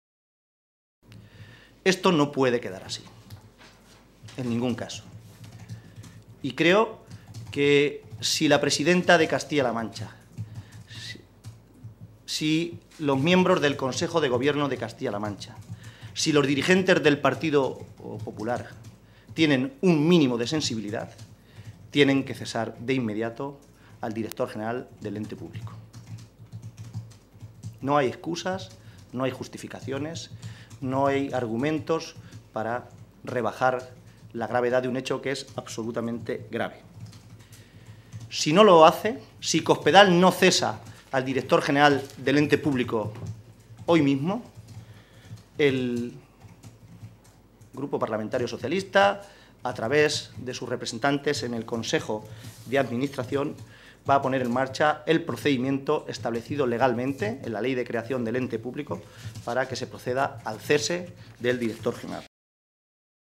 José Manuel Caballero, secretario General del Grupo Parlamentario Socialista
Cortes de audio de la rueda de prensa